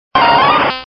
Archivo:Grito de Weepinbell.ogg